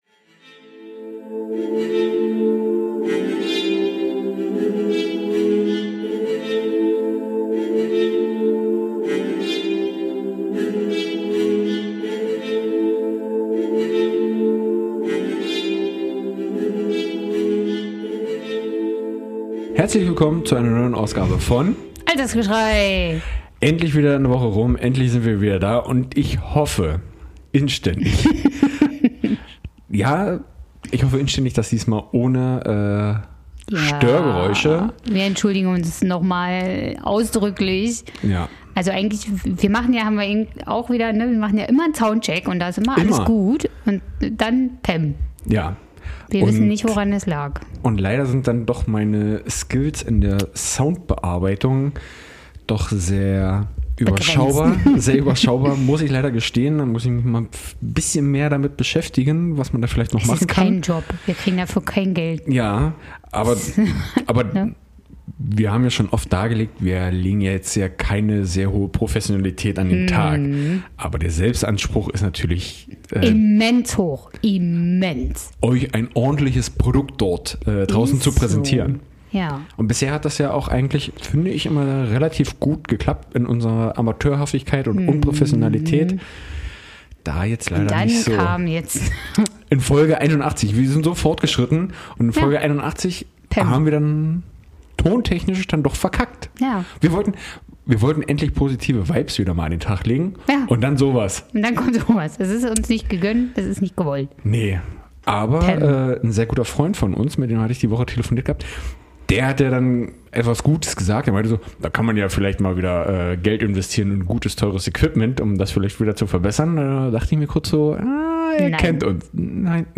Umso mehr freuen wir uns diese Woche, dass wir wie gewohnt in besserer Qualität wieder euch beglücken können, mit unseren zarten Stimmchen. Doch diese Woche geht es nicht um unsere Stimmen, sondern um unsere feinen Nasen und dem Geruchssinn.